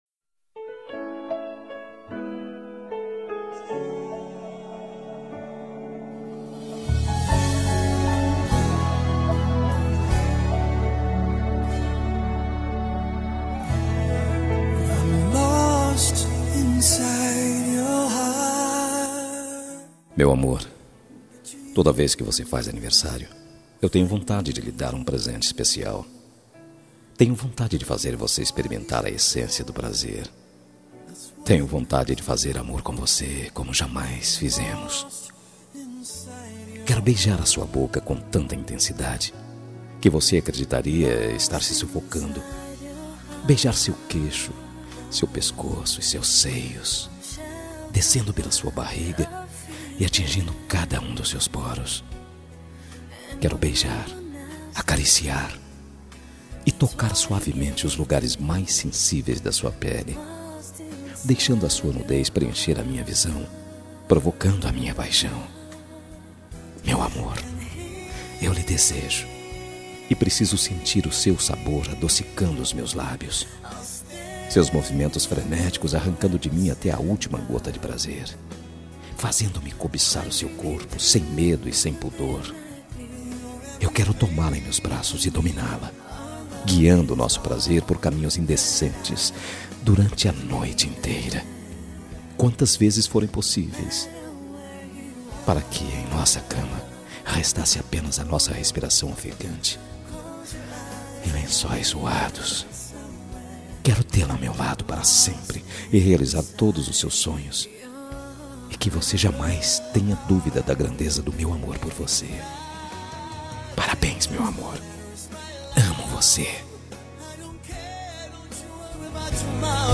Telemensagem de Aniversário Romântico – Voz Masculina – Cód:1096 – Picante